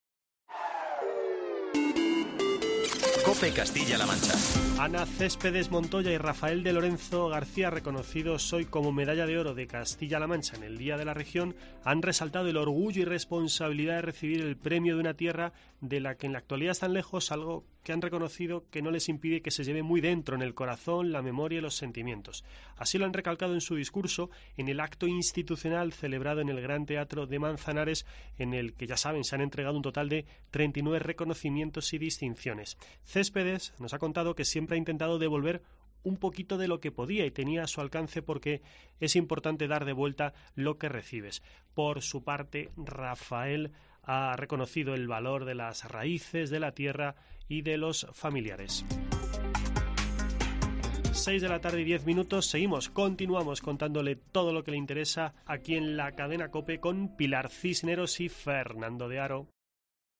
Los 39 premiados han recibido su reconocimiento en el acto del Día de Castilla-La Mancha celebrado en el Gran Teatro de Manzanares (Ciudad Real)
Último boletín